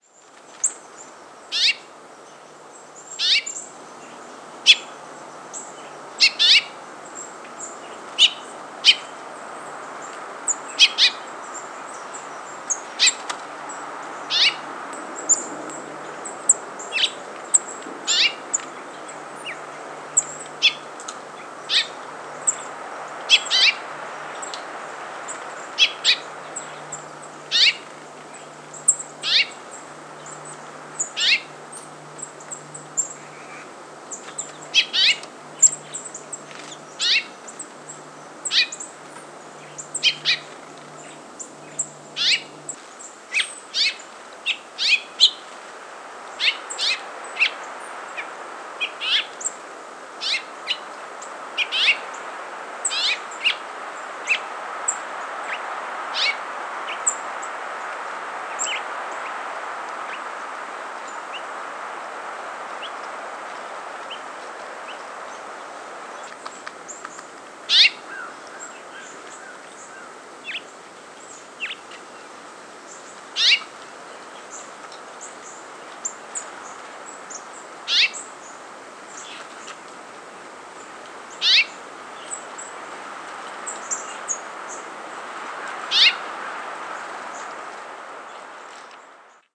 House Finch diurnal flight calls
"Su-eep" call variation from perched individual with Chipping Sparrow, House Wren, and House Finch singing in the background.